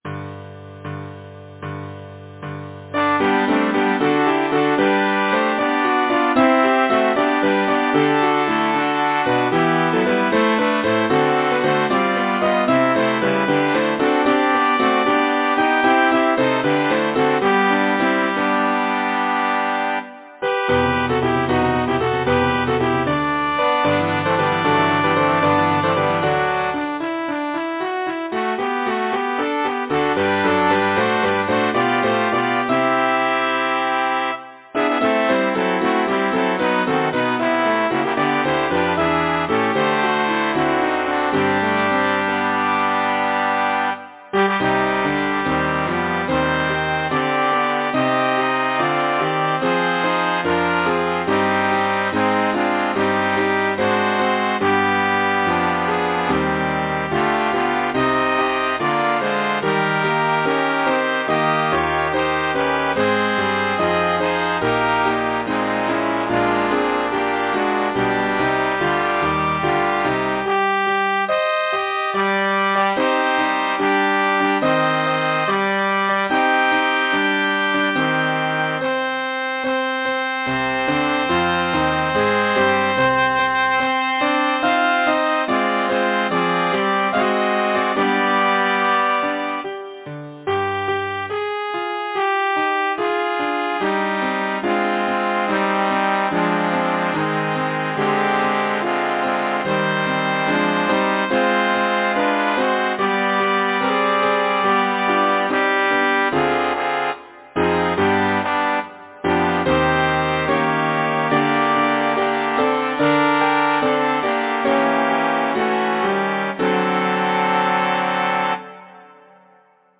Title: Wind and Sea Composer: Frank G. Cauffman Lyricist: Bayard Taylor Number of voices: 4vv Voicing: SATB Genre: Secular, Partsong
Language: English Instruments: Piano